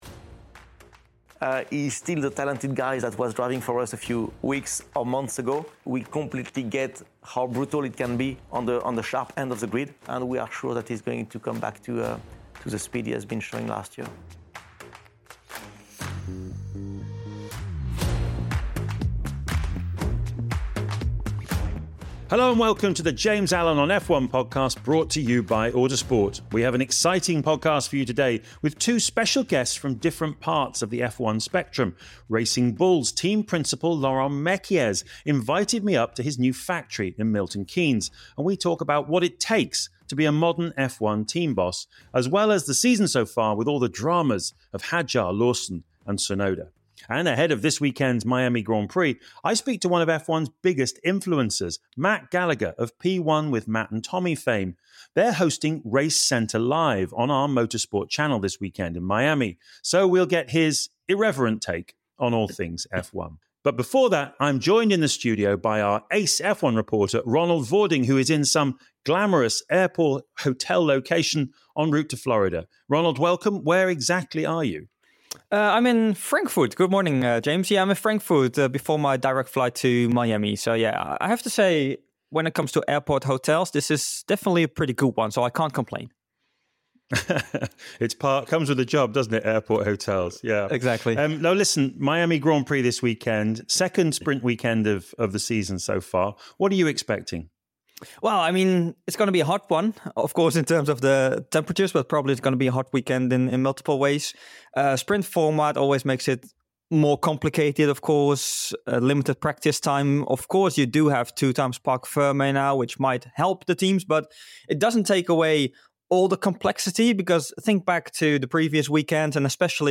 It’s Miami GP week. We have an exciting podcast with two special guests from different parts of the F1 spectrum.